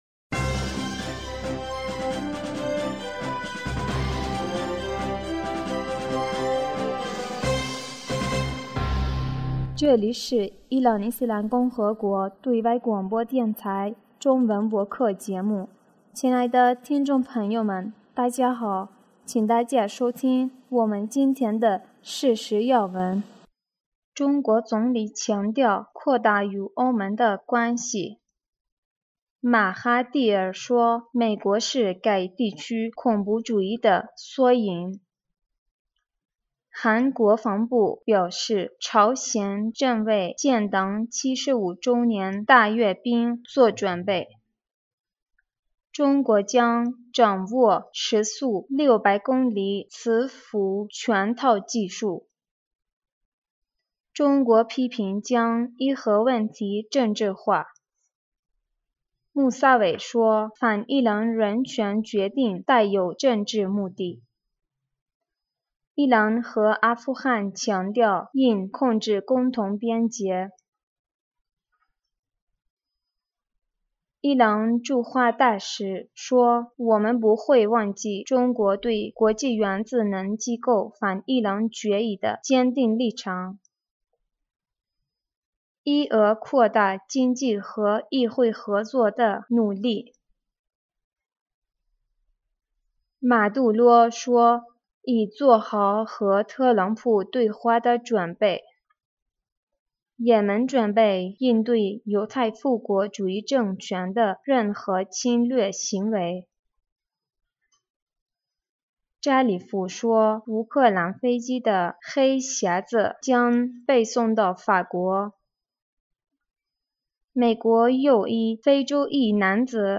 2020年6月23日 新闻